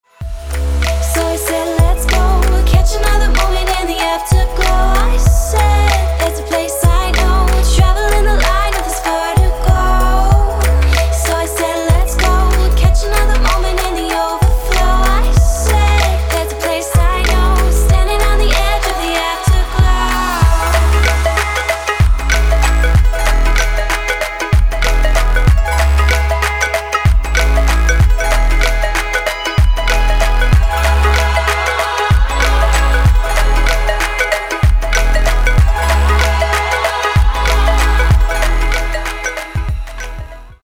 поп
женский вокал
dance
Electronic
Club House
tropical house
Vocal House
Electronic Pop